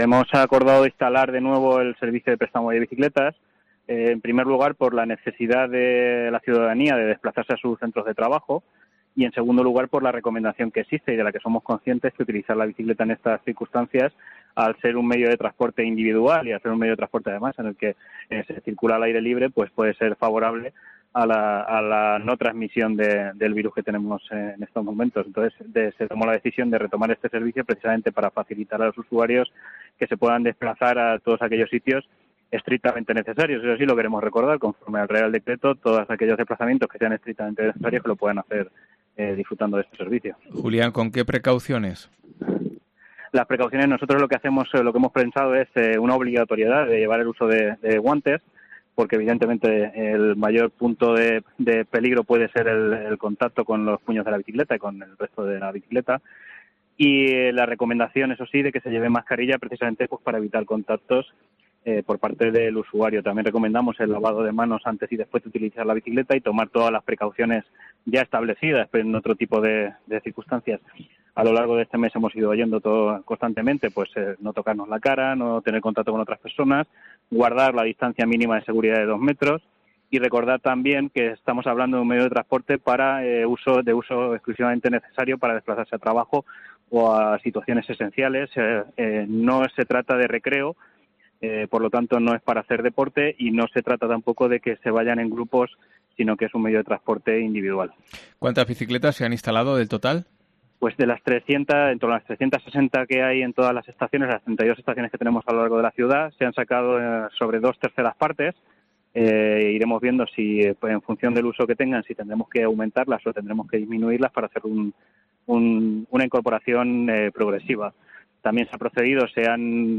ENTREVISTA
Julián Ramón, concejal de sostenibilidad del Ayuntamiento de Albacete explica las condiciones que deberán cumplir los usuarios